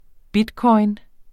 Udtale [ ˈbidˌkʌjn ]